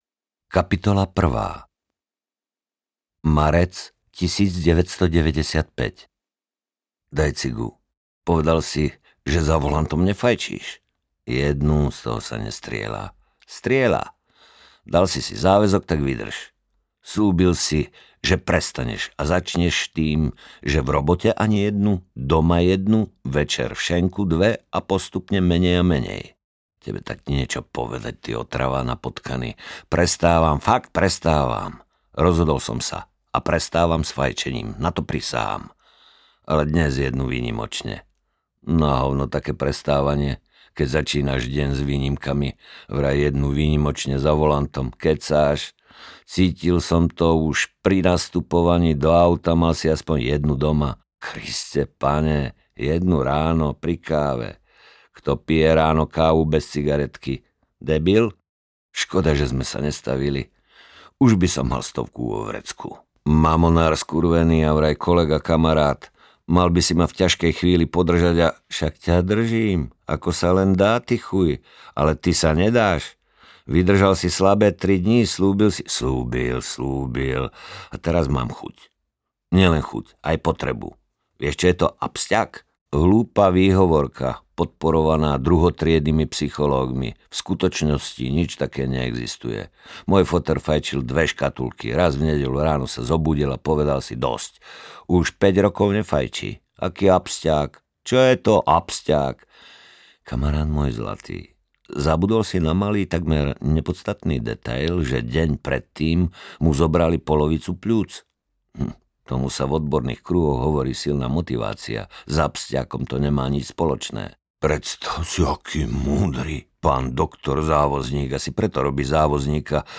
Cigaretka na dva ťahy audiokniha
Ukázka z knihy
• InterpretMarián Geišberg